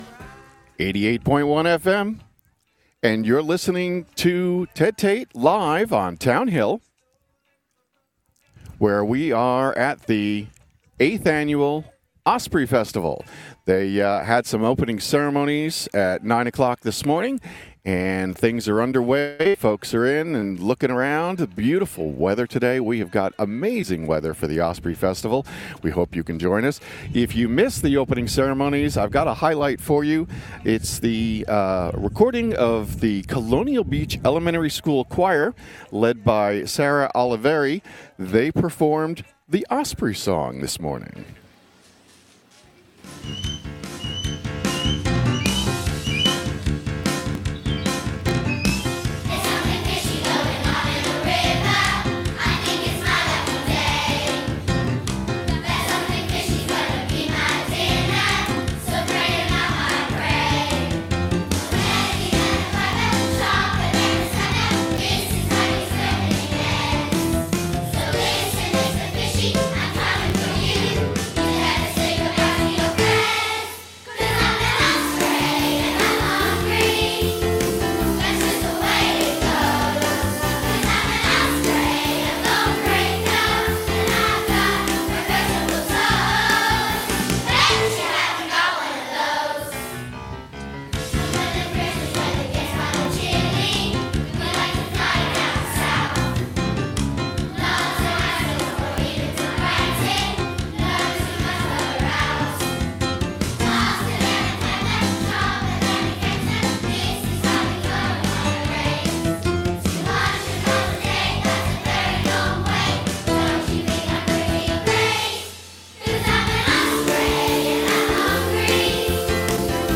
The Colonial Beach Elementary School Choir sings the Osprey Song.
Osprey opening - choir.mp3